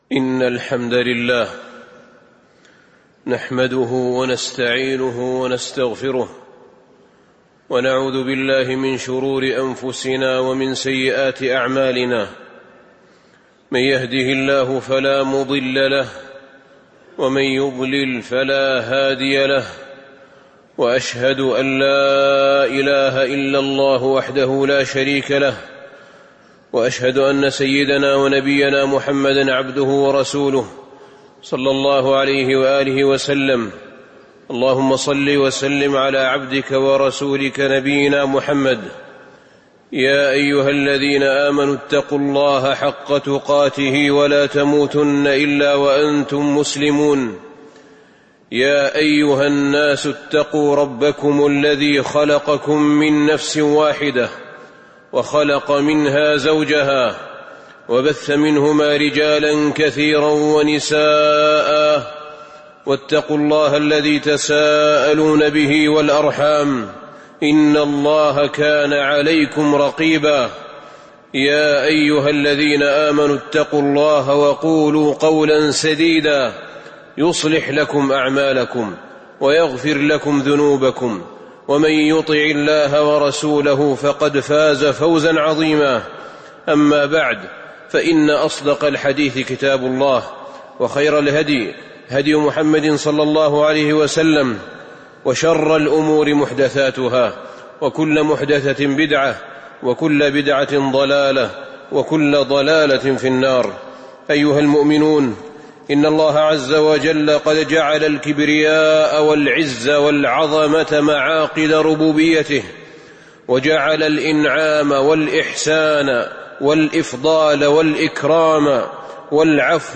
تاريخ النشر ٢٥ ربيع الأول ١٤٤٤ هـ المكان: المسجد النبوي الشيخ: فضيلة الشيخ أحمد بن طالب بن حميد فضيلة الشيخ أحمد بن طالب بن حميد فوائح المعاني بفواتح السبع المثاني